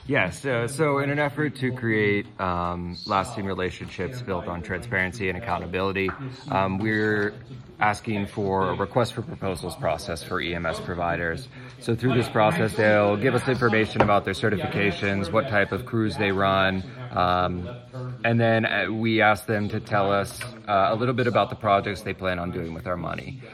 At their meeting Tuesday night, Indiana Borough Council approved applications for three grants.
Council is asking EMS providers to go through a proposal process. Council Member Jonathan Smith talked about what that entails, and what their goal is in working that process.